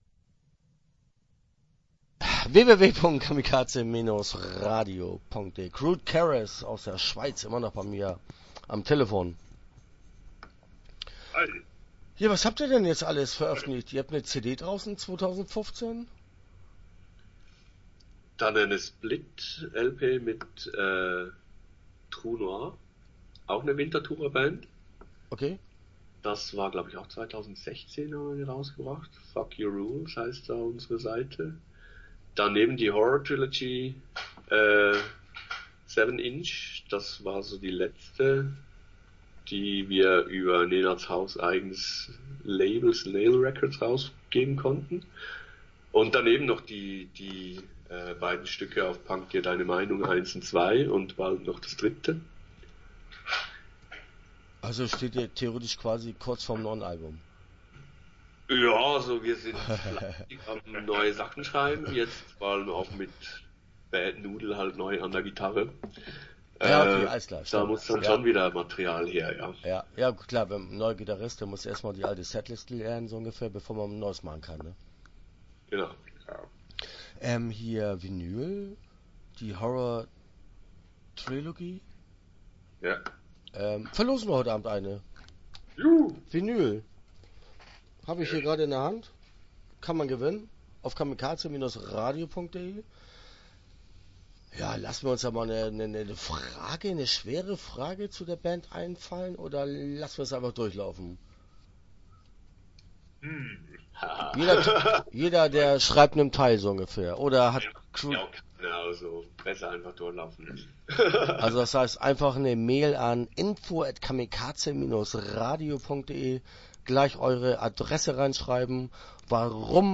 Crude Caress - Interview Teil 1 (10:49)